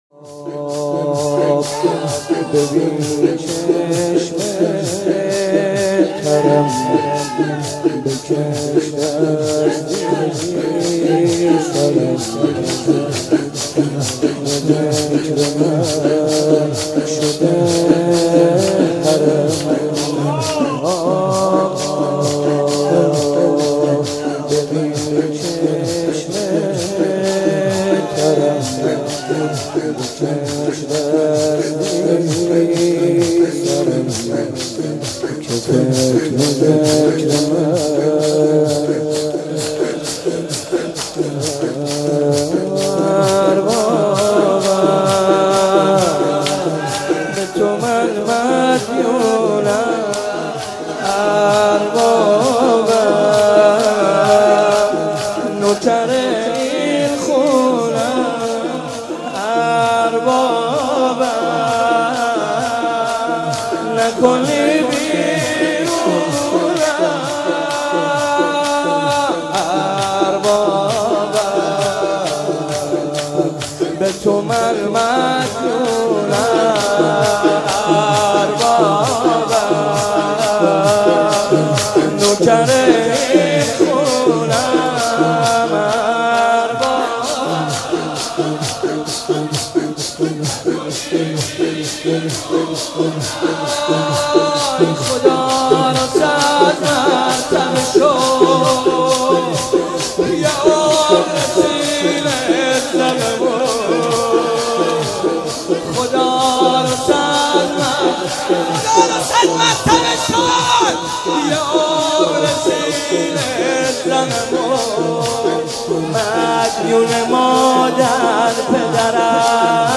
مناسبت : شهادت امام موسی‌کاظم علیه‌السلام
مداح : محمدرضا طاهری قالب : شور